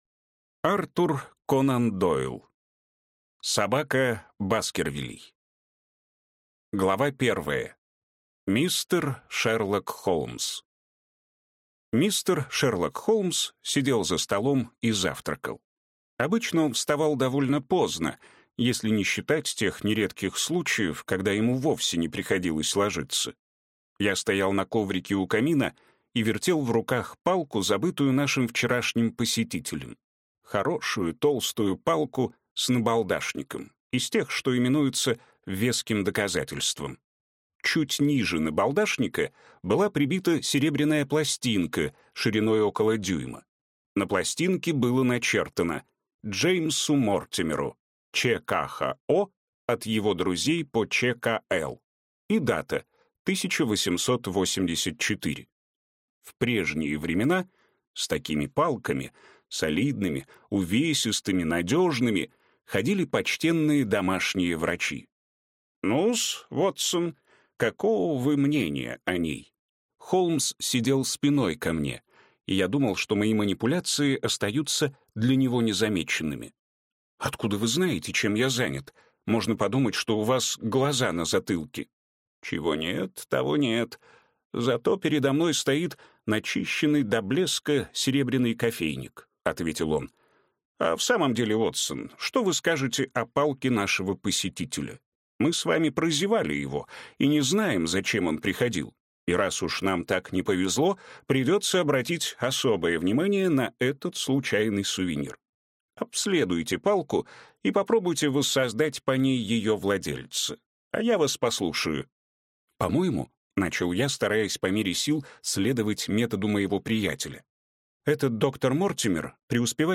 Аудиокнига Собака Баскервилей | Библиотека аудиокниг